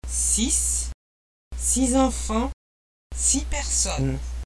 On entend la consonne quand le mot est isolé. On fait la liaison devant une voyelle. Cependant la consonne disparaît si le mot qui suit commence par une consonne.